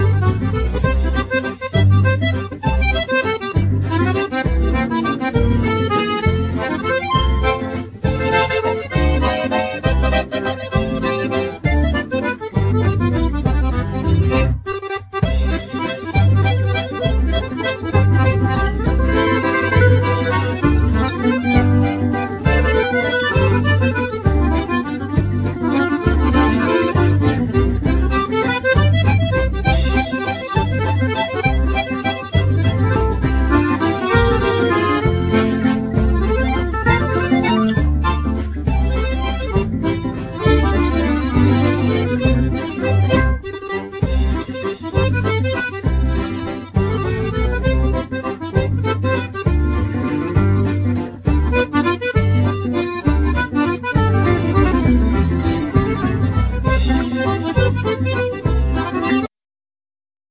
Violin
Viola
Cello
Flute
Clarinet
Harp
Drums
Accordeon
Guitars,Percussions